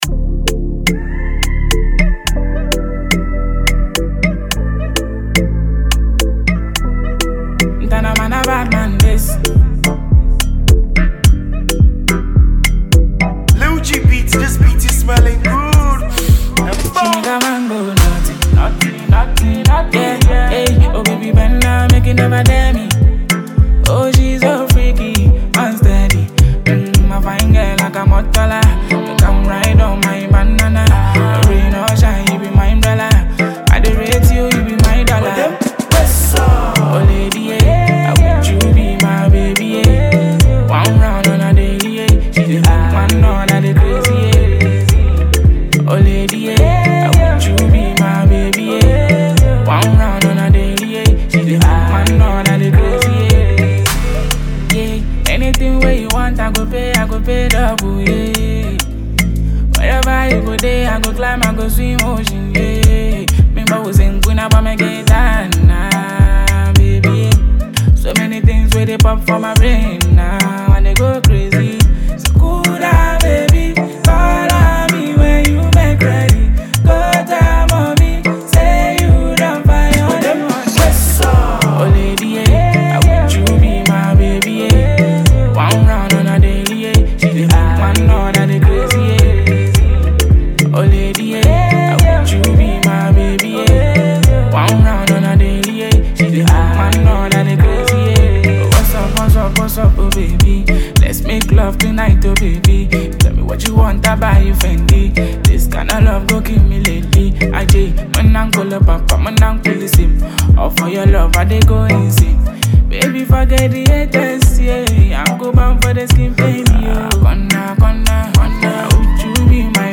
With soulful melodies and sincere lyrics